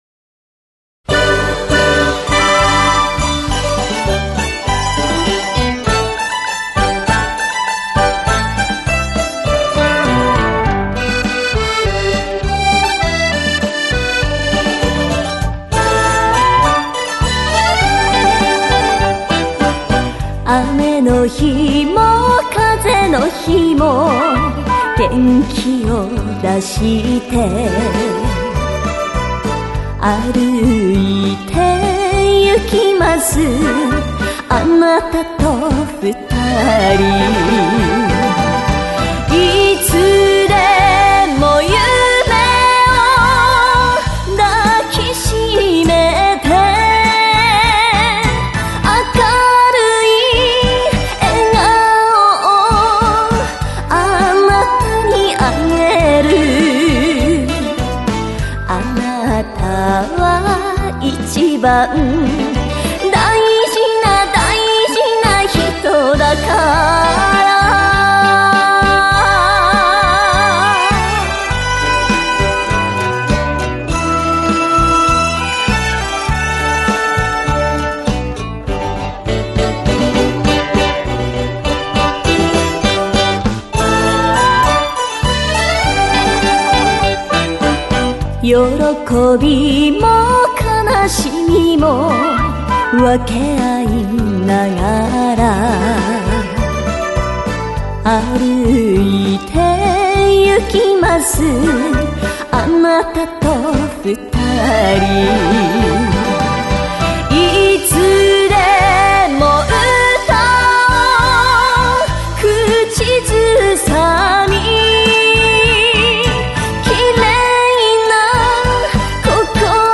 素軽いリズム感のポップス調やスローなバラードから,うなり節とこぶし
回しを利かせた演歌まで変化に富んだ歌声が楽しめる。